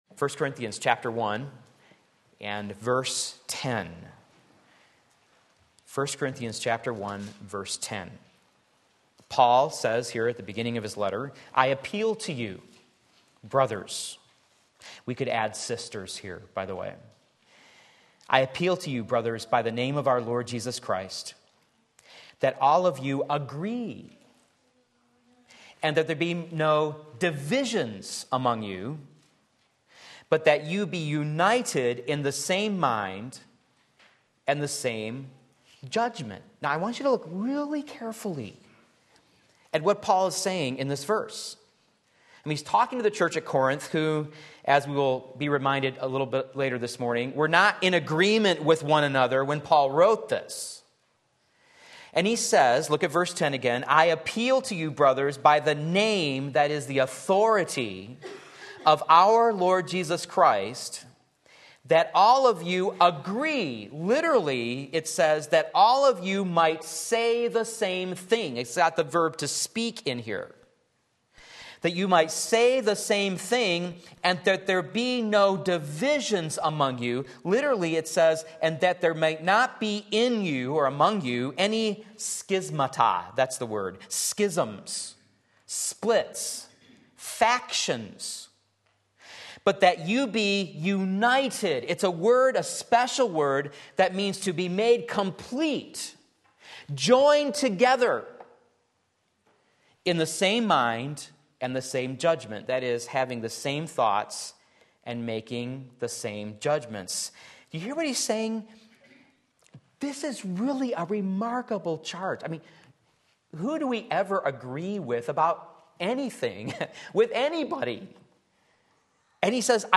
Sermon Link
Unity in the Body of Christ 1 Corinthians 1:10 Sunday Morning Service